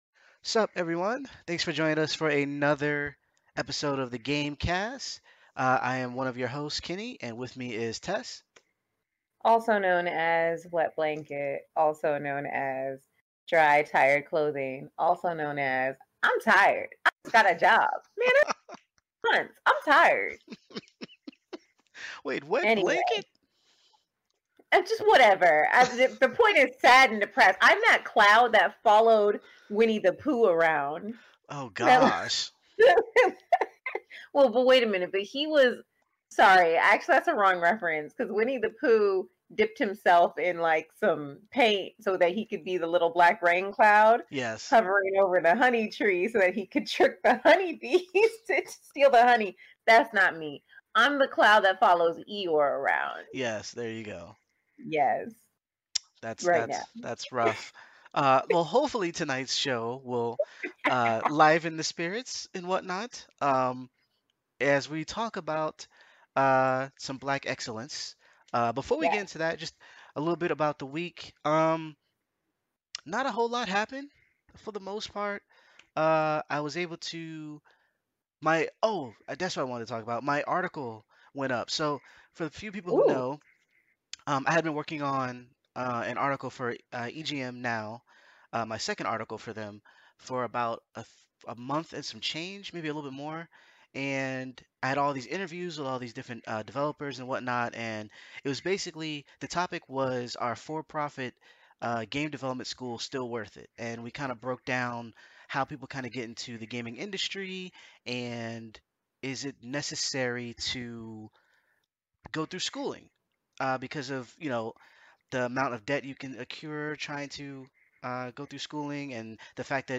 We start off with a brief wrap up of our week - there's a break in the dialogue due to recording error (our bad) - before speaking about HBO's Insecure.